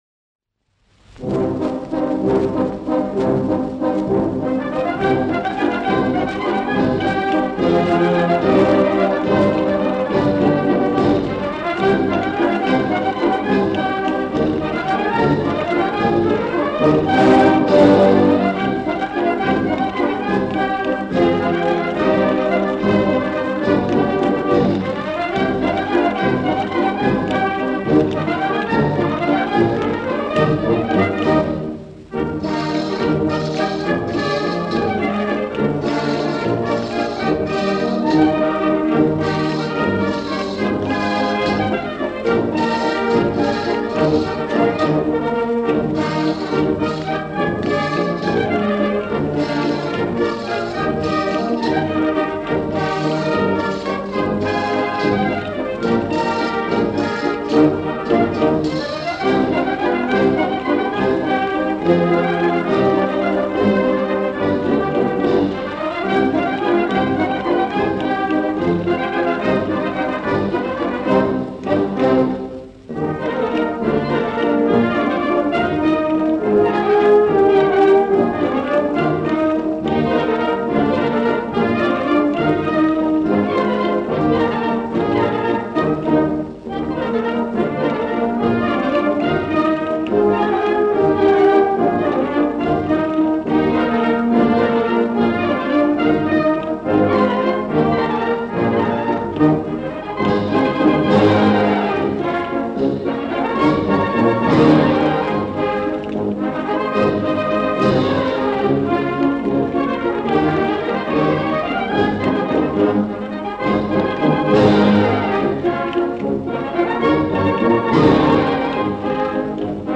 Со старых пластинок с одного из сайтов Па-д эспань, может у кого есть в качестве, именно такое исполнение